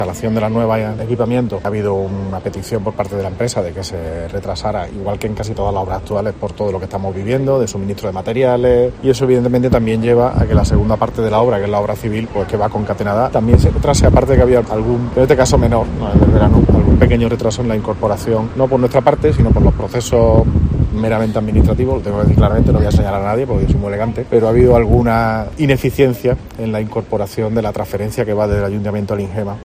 Escucha al alcalde, José María Bellido, sobre el retraso en la apertura de La Ciudad de los Niños